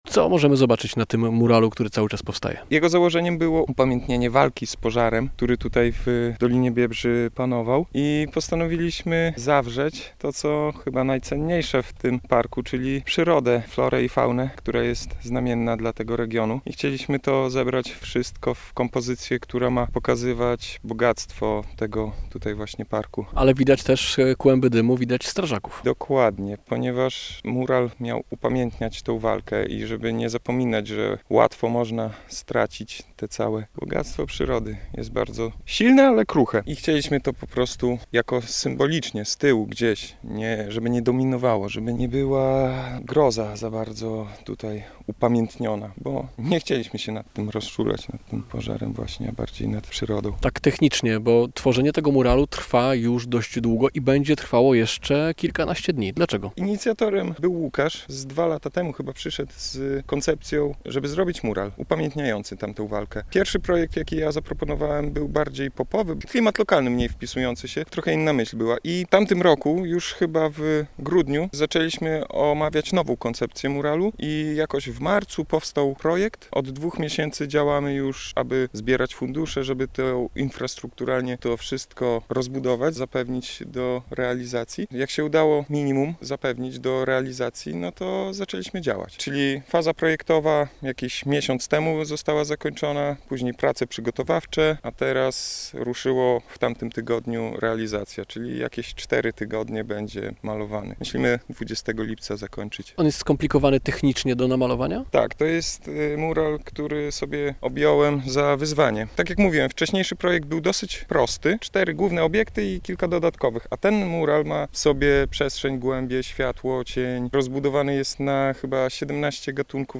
Pożar w Biebrzańskim Parku Narodowym na muralu - z